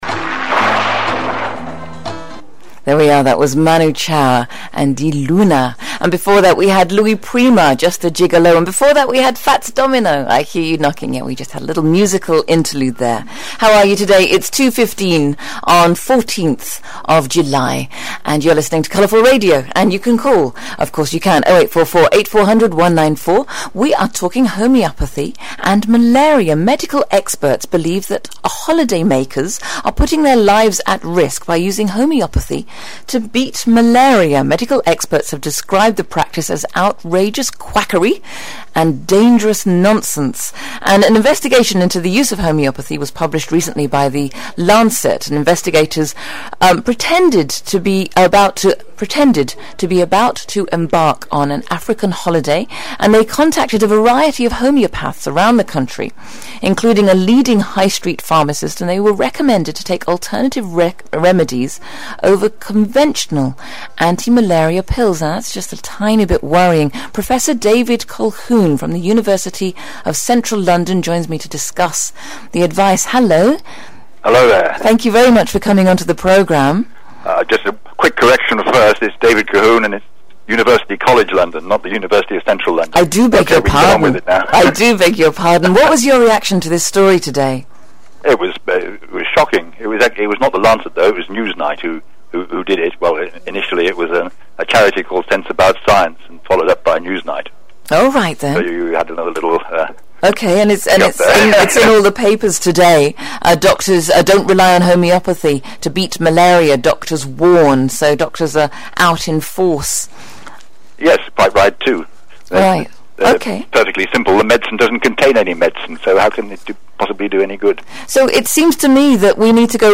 Talk show interview